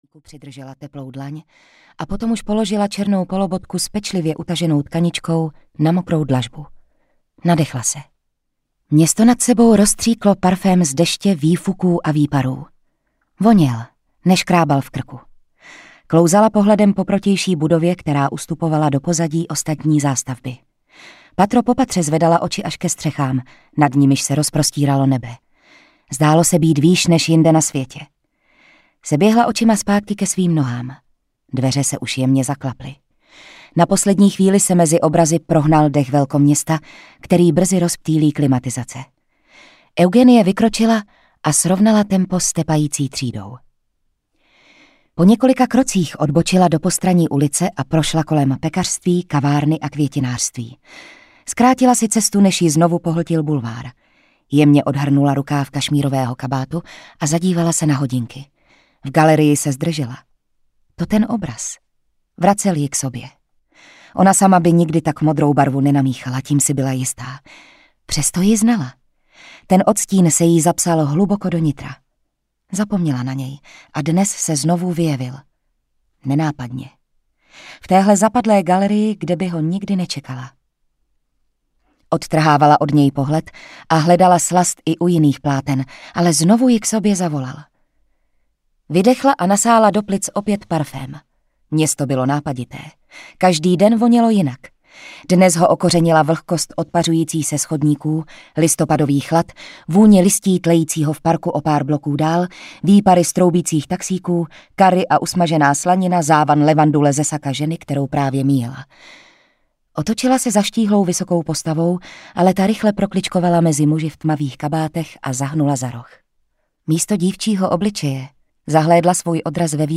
Eugenie audiokniha
Ukázka z knihy